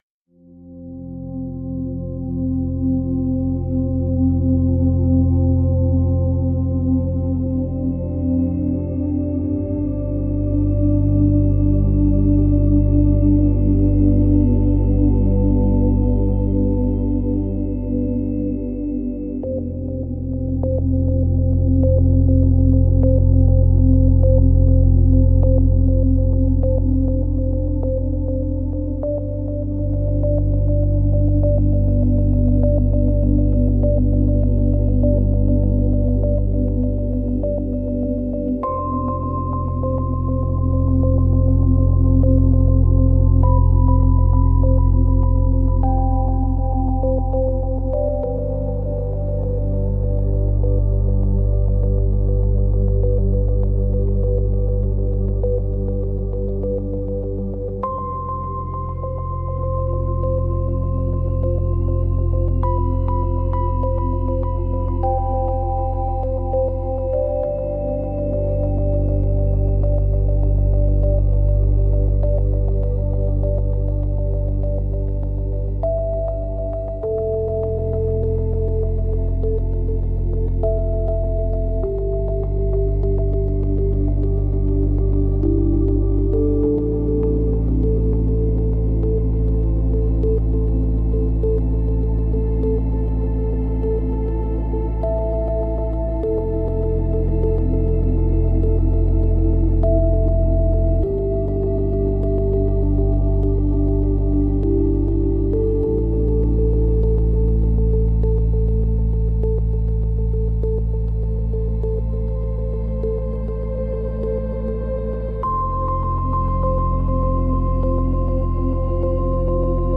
Space-Ambient-Album